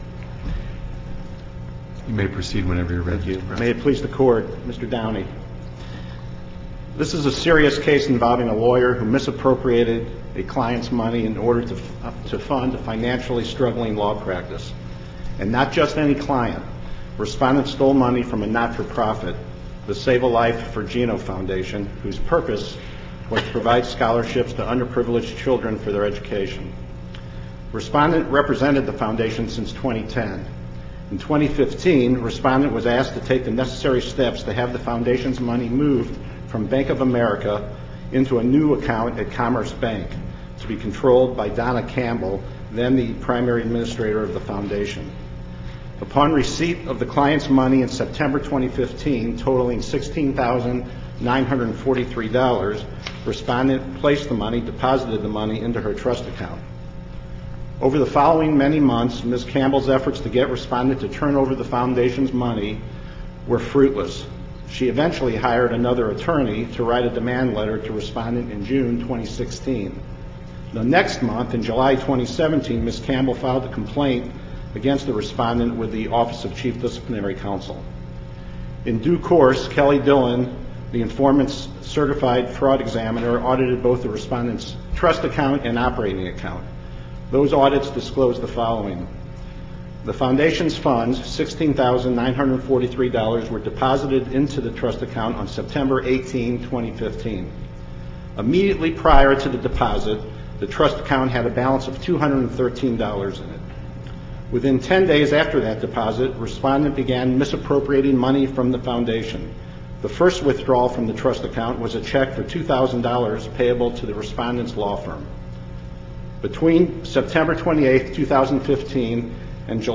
MP3 audio file of oral arguments in SC96731